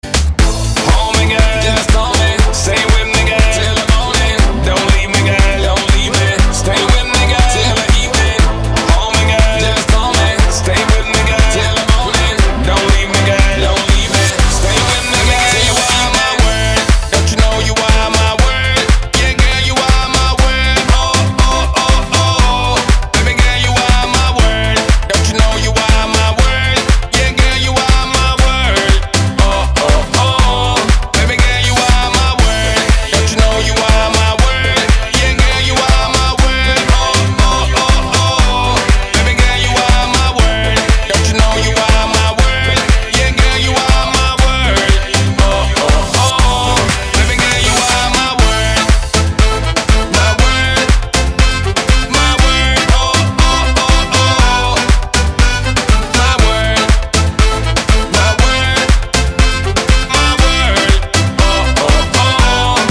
• Качество: 320, Stereo
красивые
веселые
заводные